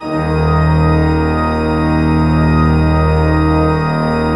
Index of /90_sSampleCDs/Propeller Island - Cathedral Organ/Partition F/MAN.V.WERK M